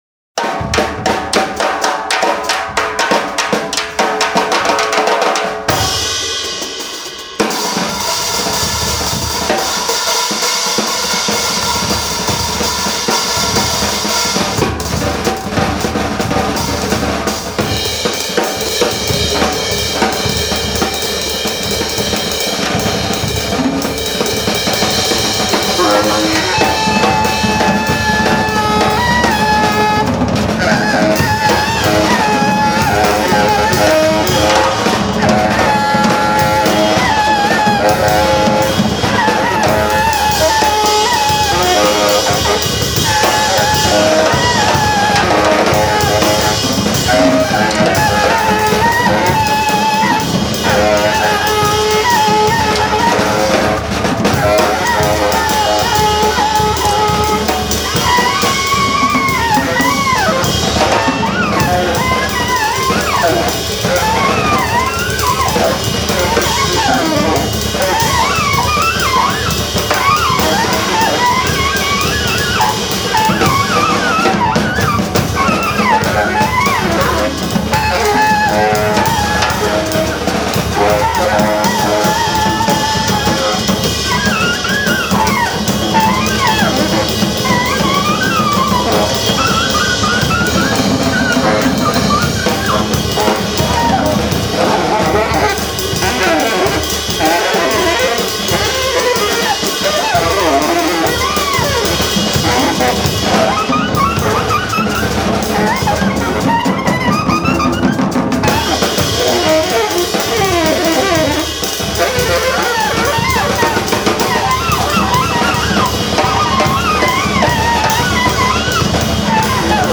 Recorded live in Milwaukee in 2011
appropriately combustible
Sticking mostly to tenor saxophone
powering the saxophonist with gale-force bursts
duo LP